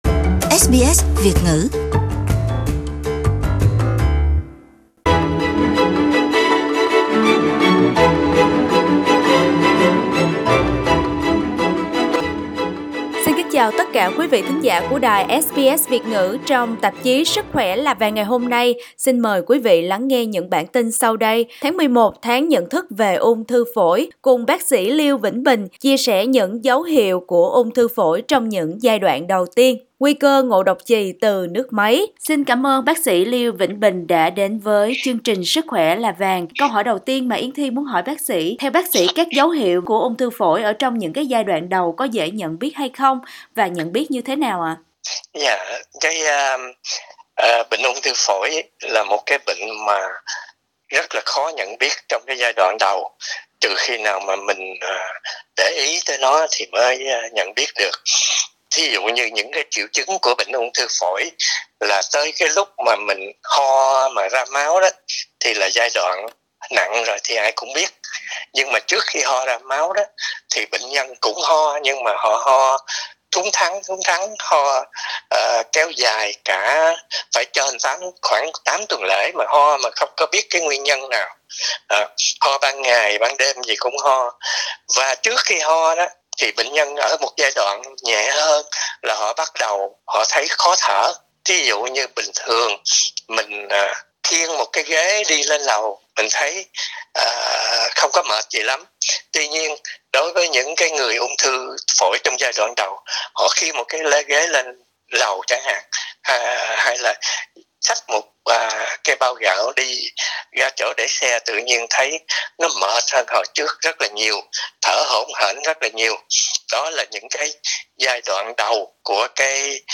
Trò chuyện